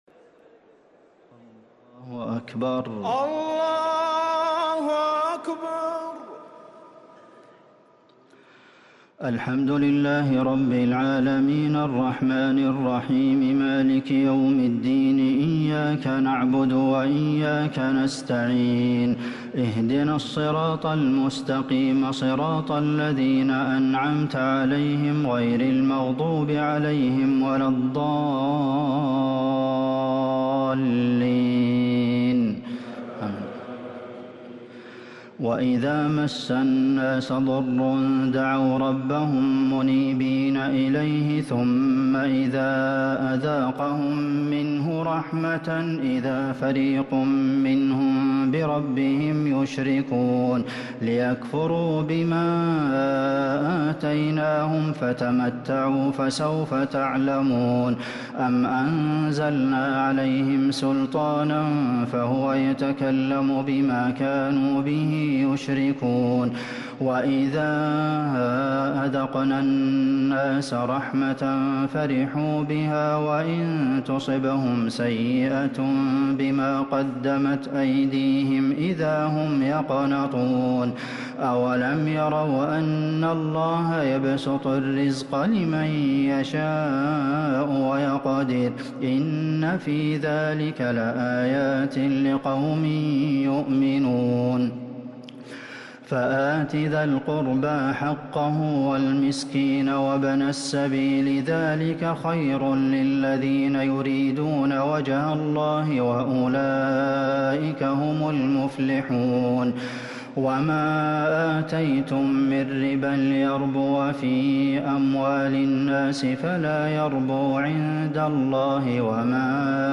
تراويح ليلة 24 رمضان 1444هـ آخر سورة الروم (33_60) و سورة لقمان كاملة | taraweeh 24 st night Ramadan 1444H surah Ar-Room and Luqman > تراويح الحرم النبوي عام 1444 🕌 > التراويح - تلاوات الحرمين